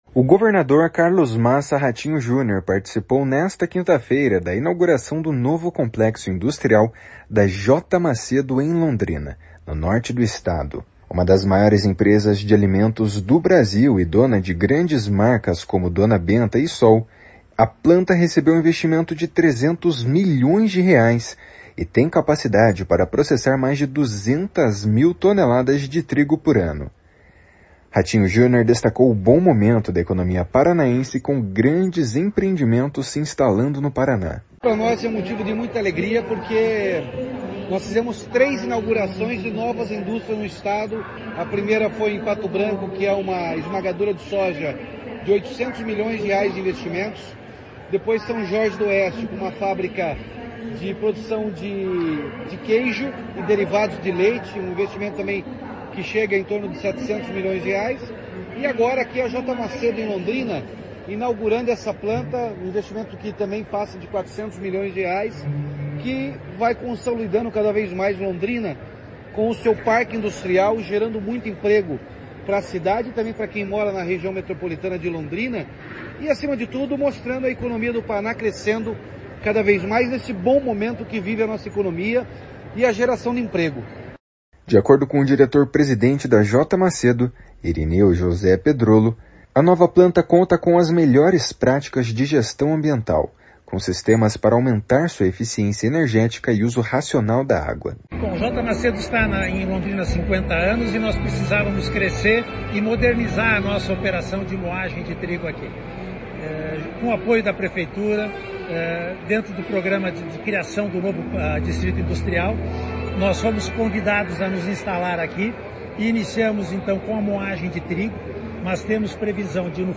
O prefeito Tiago Amaral ressaltou a importância da empresa, presente na cidade há mais de 50 anos.
Guto Silva, secretário estadual das Cidades, destacou a grandeza de Londrina.